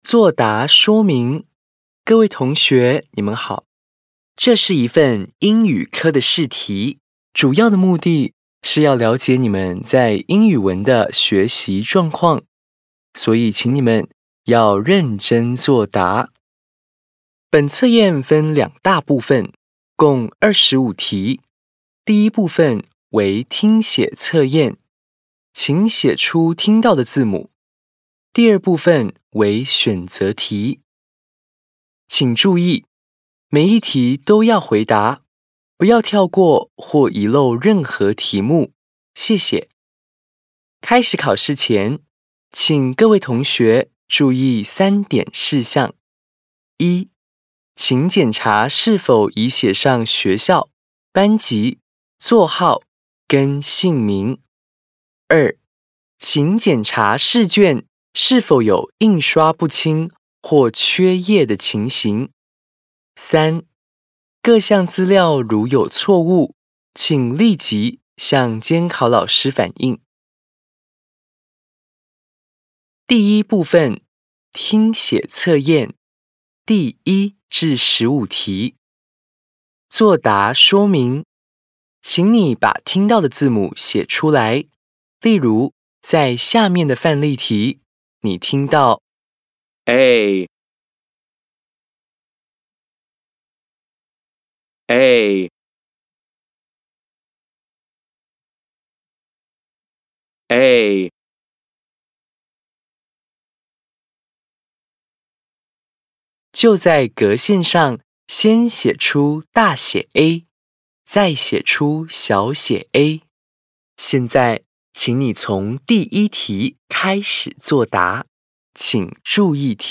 113年成長測驗英語科4年級試卷聽力檔.mp3